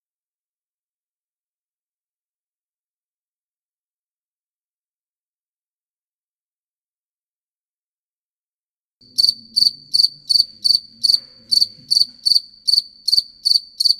Channels (Mono/Stereo): Mono